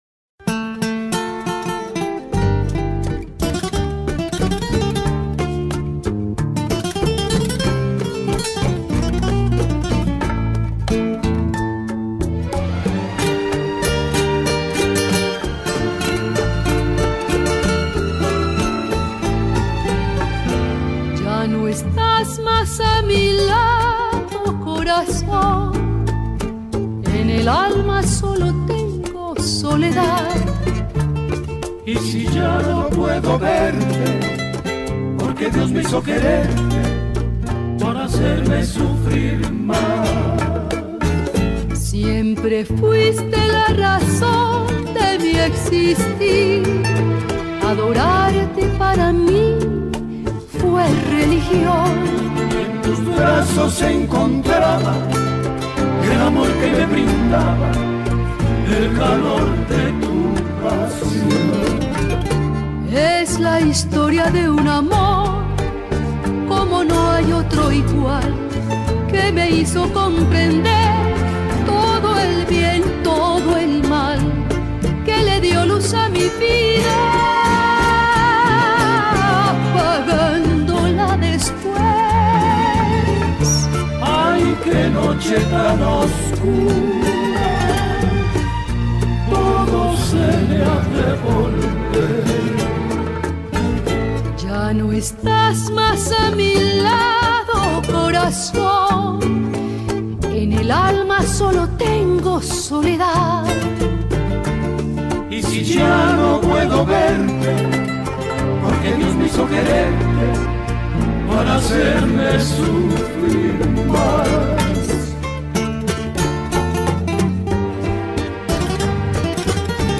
Spanish song